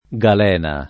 galena [ g al $ na ] s. f.